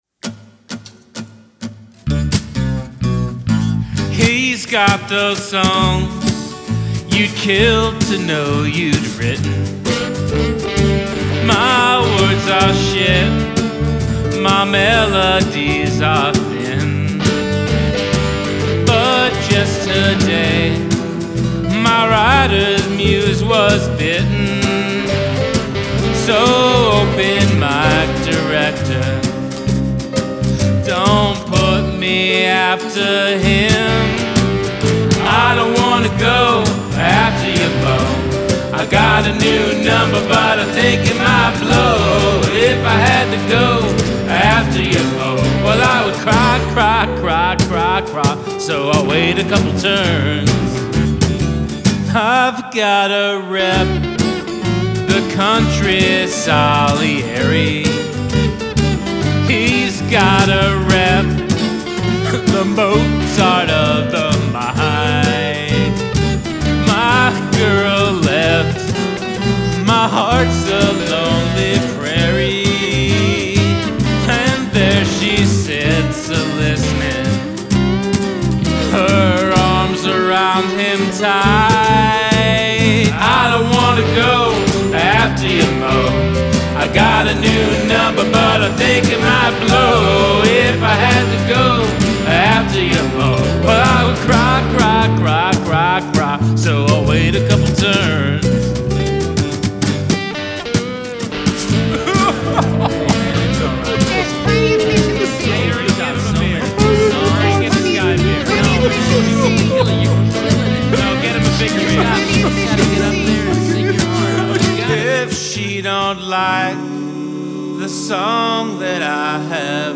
This is sloppy as Hell, and still very compelling.